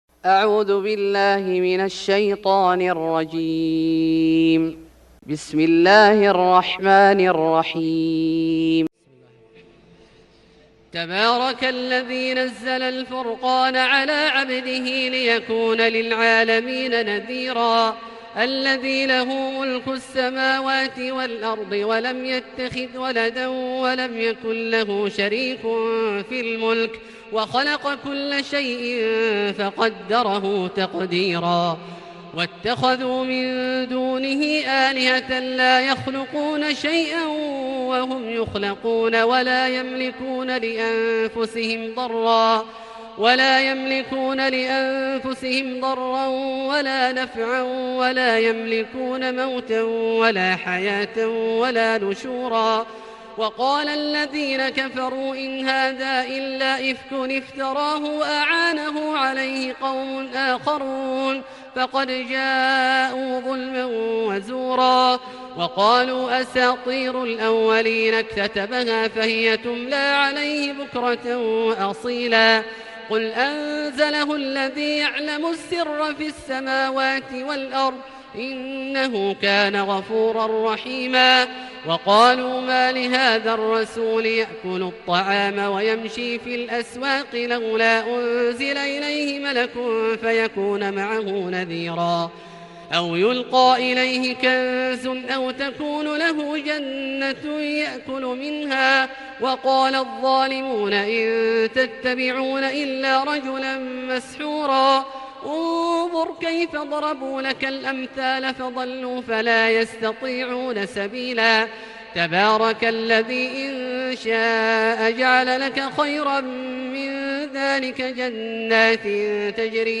سورة الفرقان Surat Al-Furqan > مصحف الشيخ عبدالله الجهني من الحرم المكي > المصحف - تلاوات الحرمين